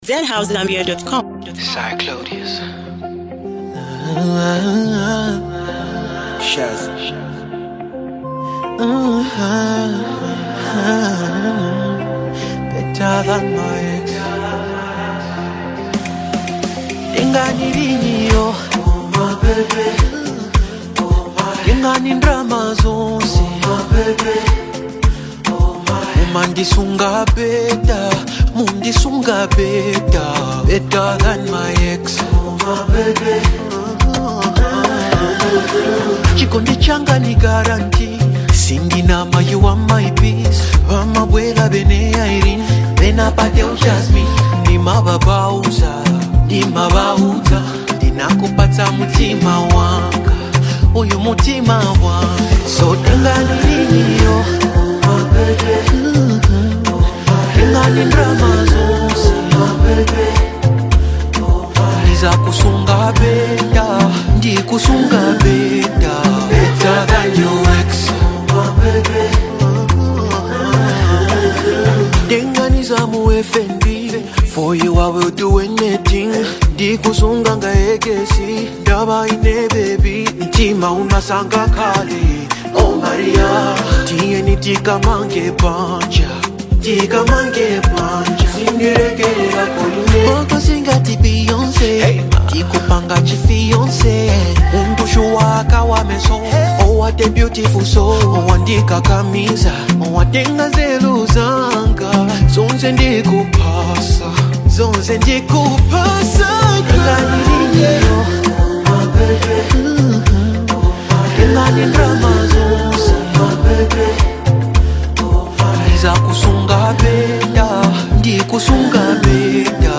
smooth vibes, catchy melodies and undeniable swagger
This one’s a certified mood!